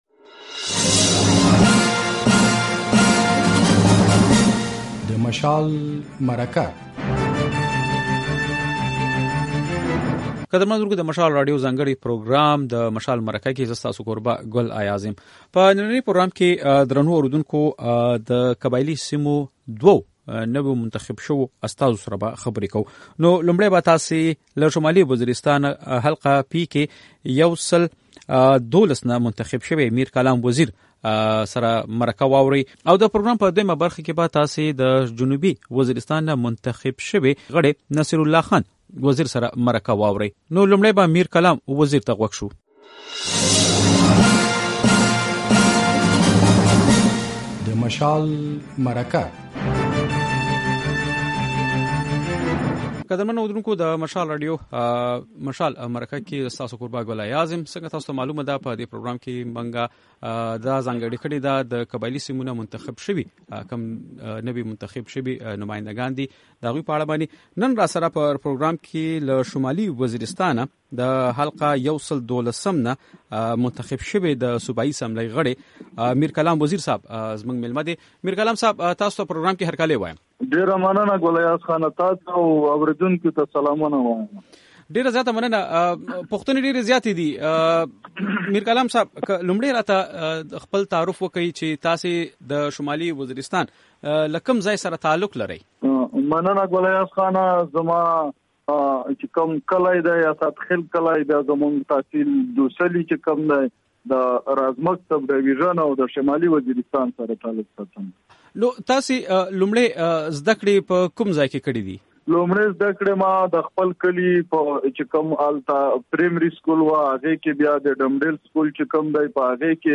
له میرکلام وزیر او نصیرالله وزیر سره د مشال مرکه